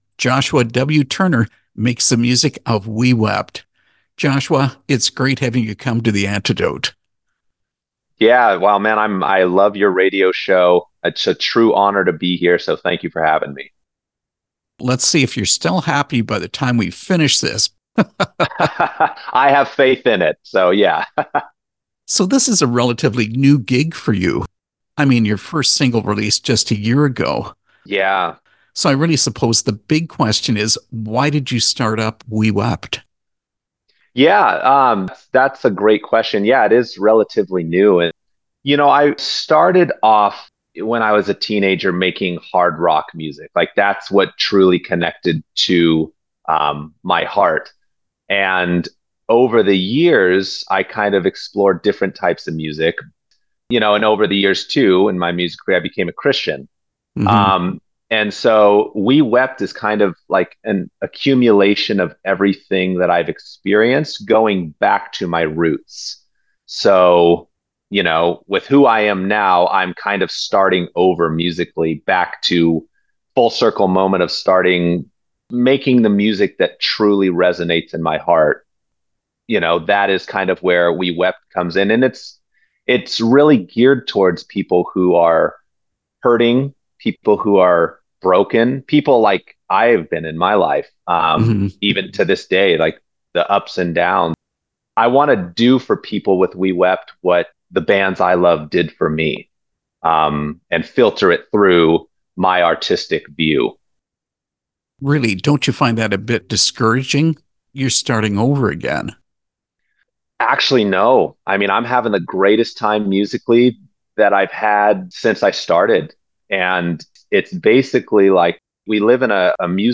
Interview with We Wept